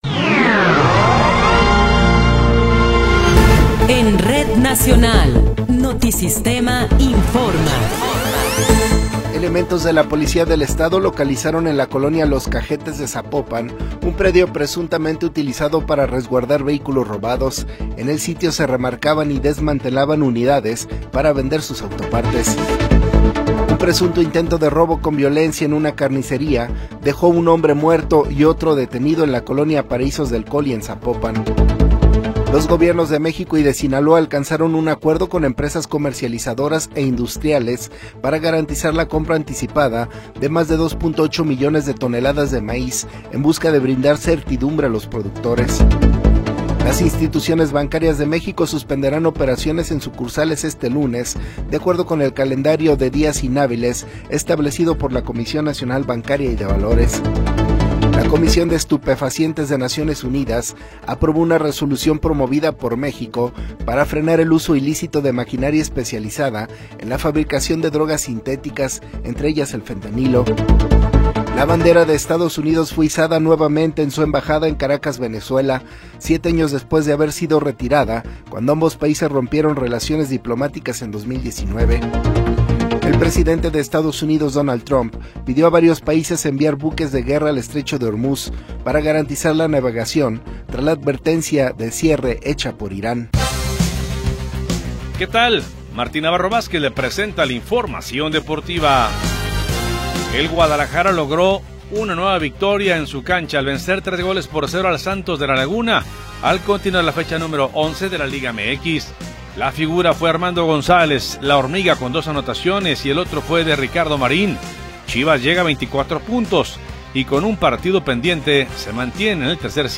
Noticiero 21 hrs. – 14 de Marzo de 2026
Resumen informativo Notisistema, la mejor y más completa información cada hora en la hora.